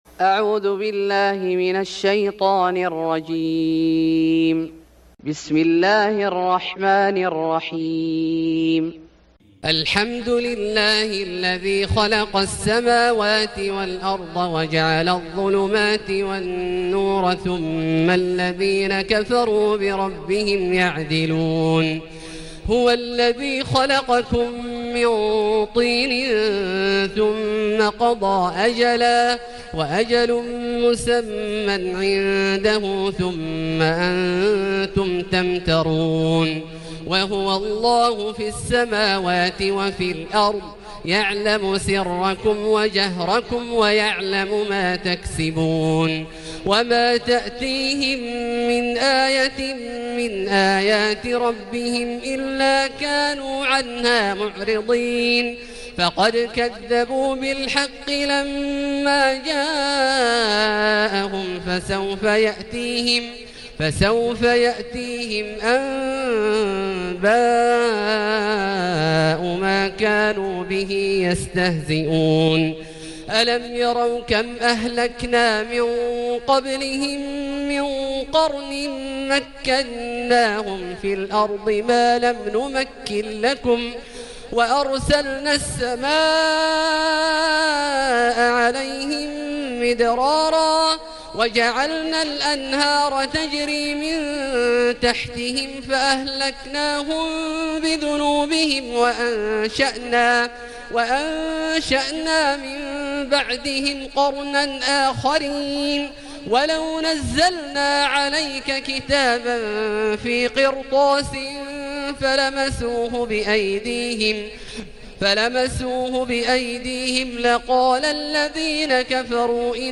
سورة الأنعام Surat Al-A'nam > مصحف الشيخ عبدالله الجهني من الحرم المكي > المصحف - تلاوات الحرمين